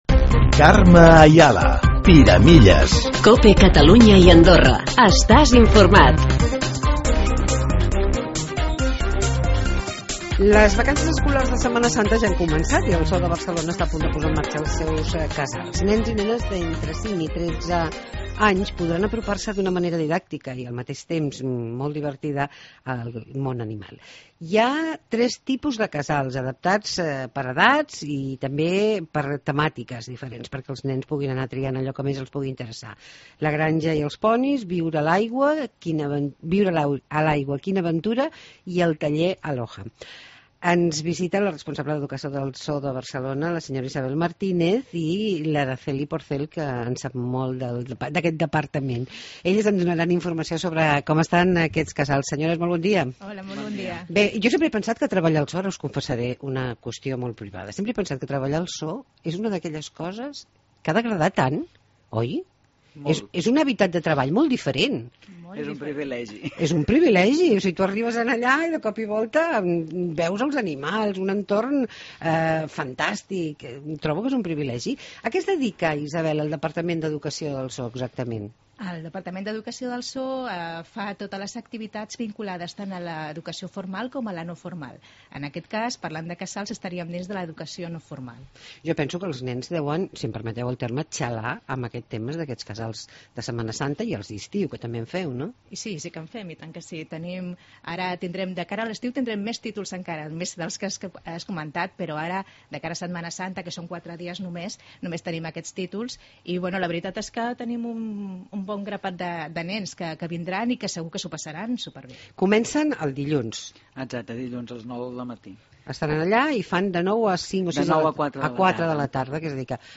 Entrevista Zoo de Barcelona